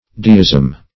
Deism \De"ism\ (d[=e]"[i^]z'm), n. [L. deus god: cf. F.